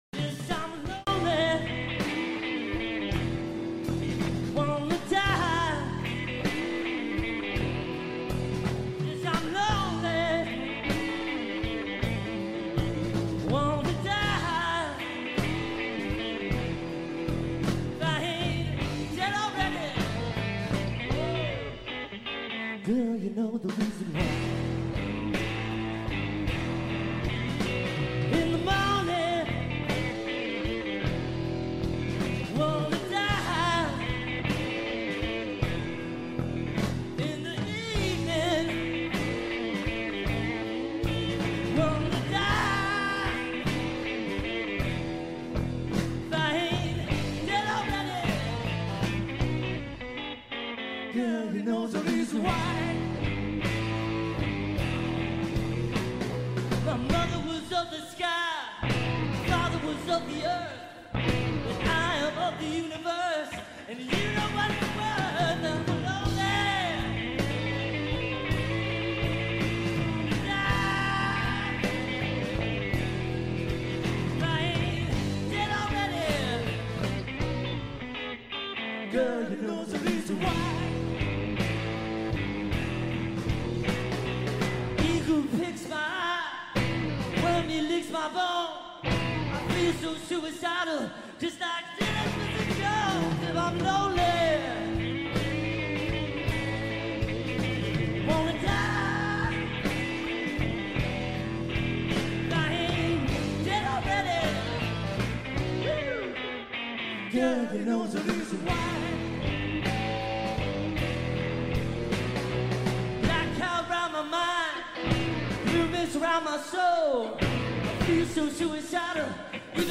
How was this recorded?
(Live)